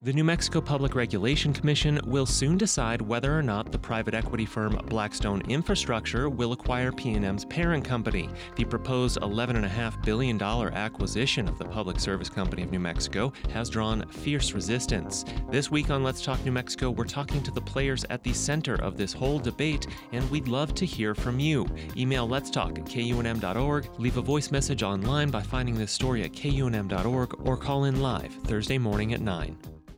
Weekly public affairs program featuring interviews with policy makers, advocates, elected officials, artists, musicians and other news makers along with live phone calls from listeners.